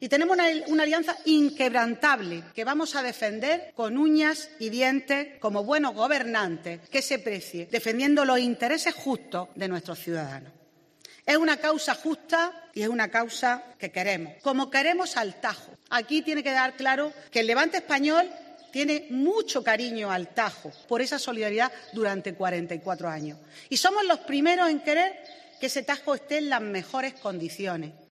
Carmen Crespo, consejera de Agricultura de la Junta de Andalucía